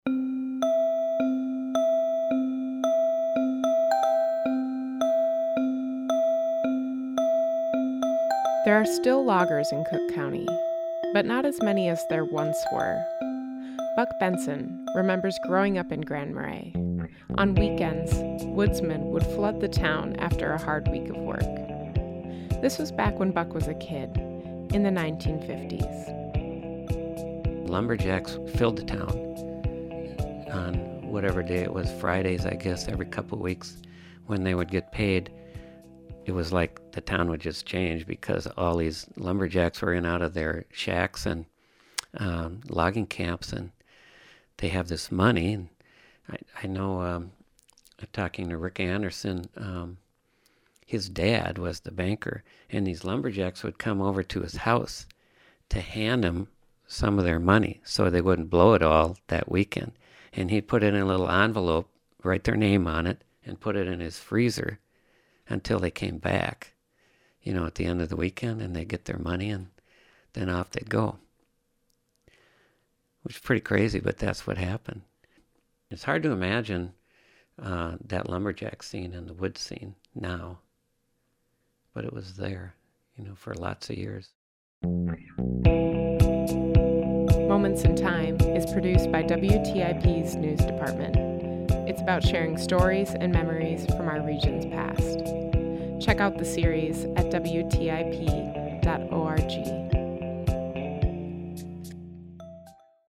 In Moments in Time, we speak with community members about their memories from different periods of our region's past to help foster an appreciation and understanding of the community in which we find ourselves today.